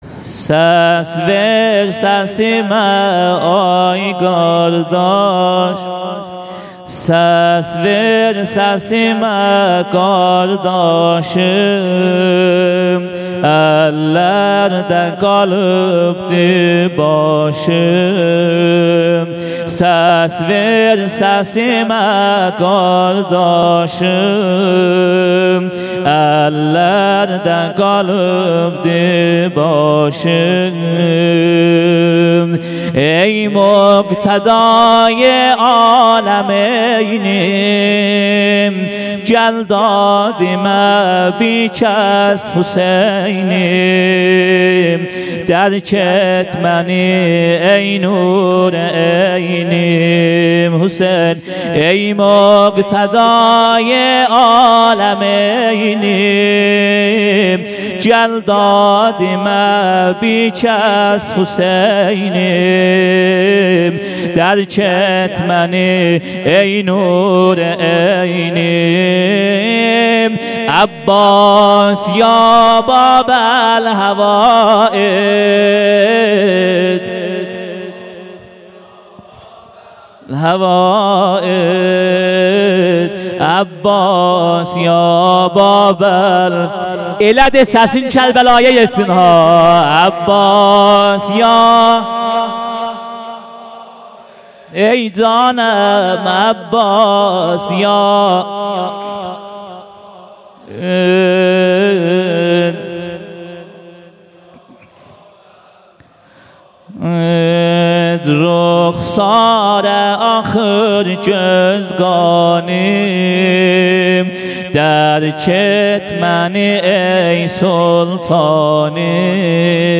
بخش اول سینه زنی